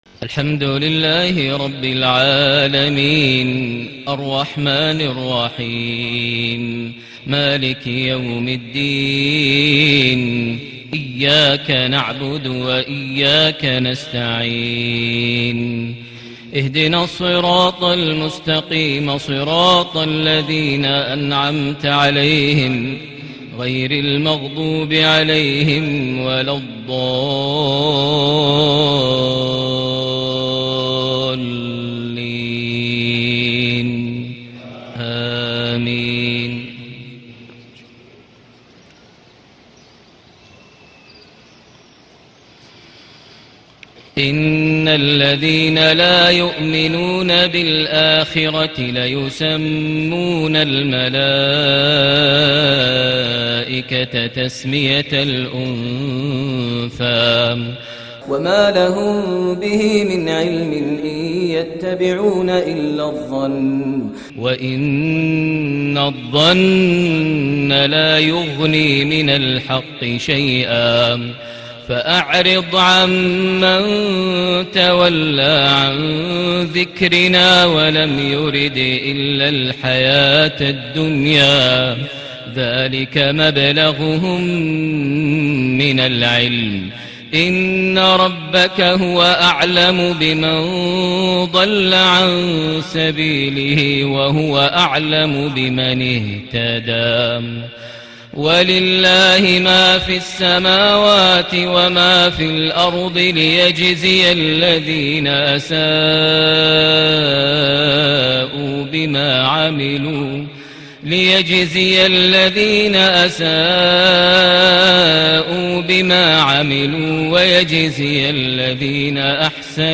صلاة المغرب ٣٠ محرم ١٤٣٨هـ سورة النجم ٢٧-٤٢ > 1438 هـ > الفروض - تلاوات ماهر المعيقلي